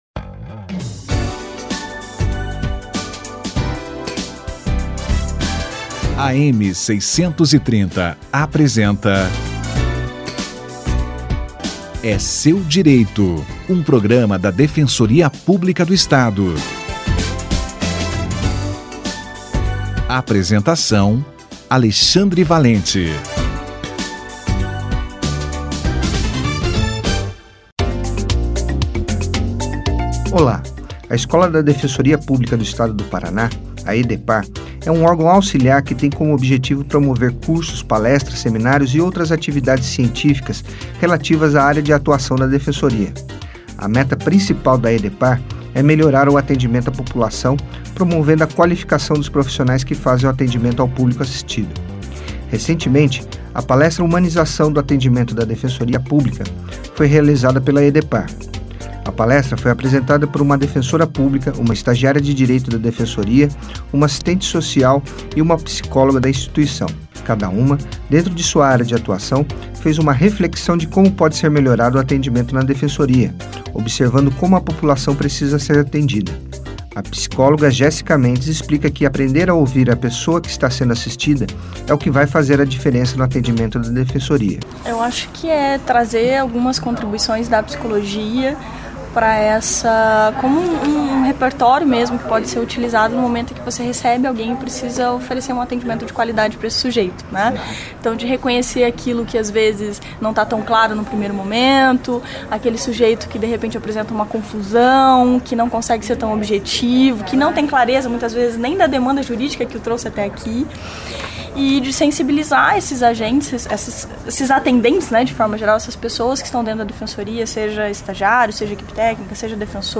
Atuação da EDEPAR na atuação do atendimento da Defensoria Pública - Entrevista